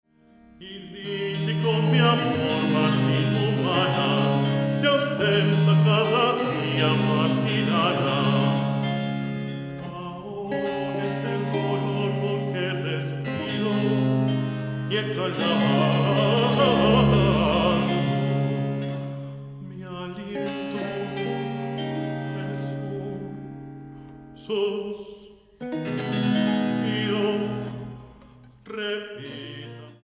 flautas de pico